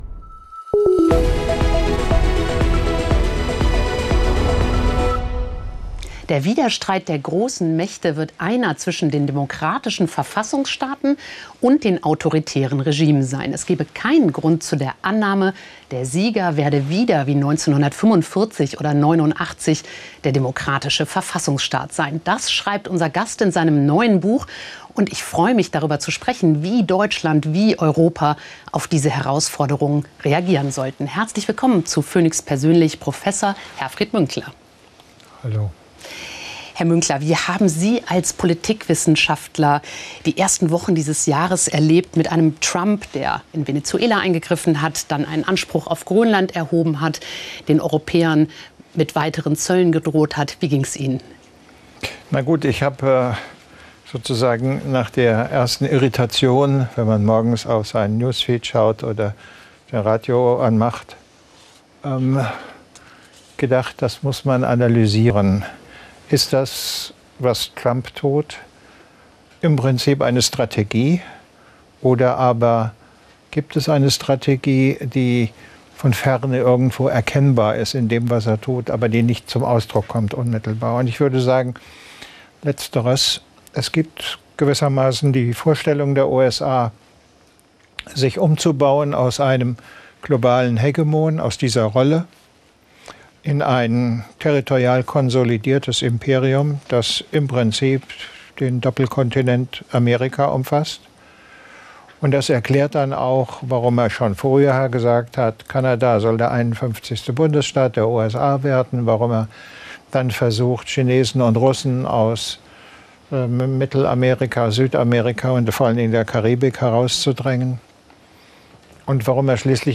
Herfried Münkler (Politikwissenschaftler) zu Gast